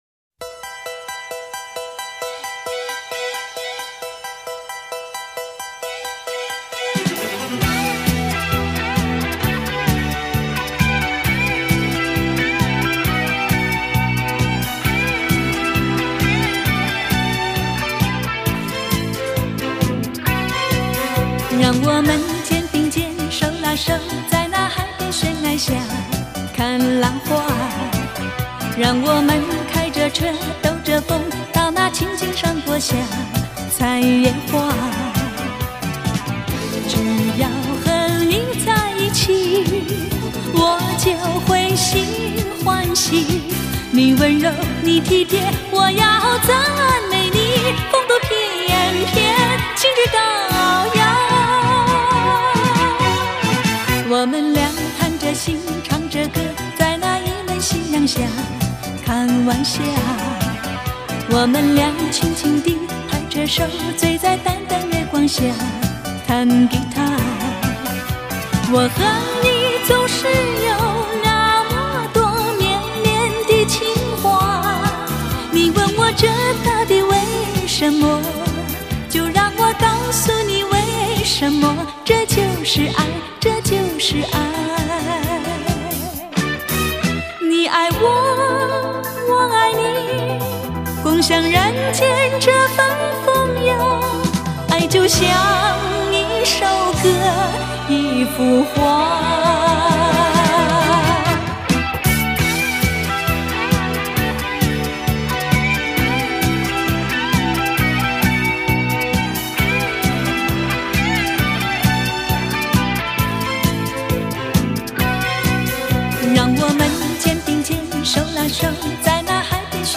黄莺般轻柔的歌声